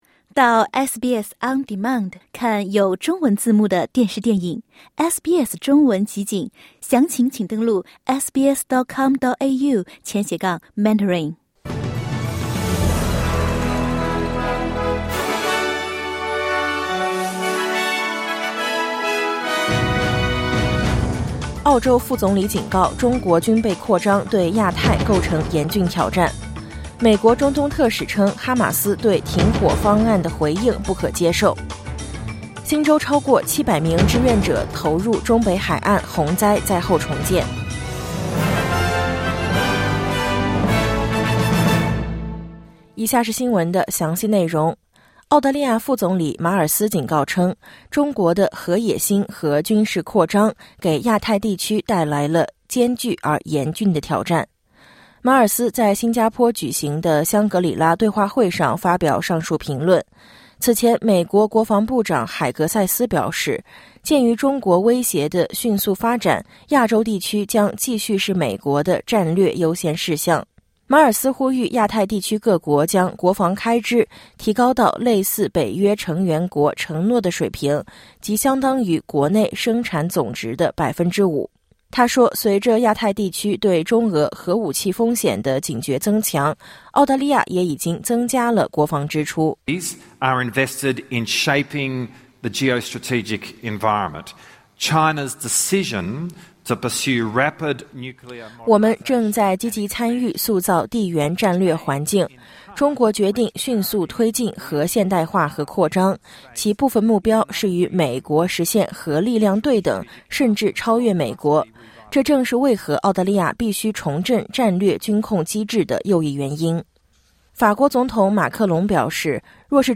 SBS早新闻（2025年6月1日）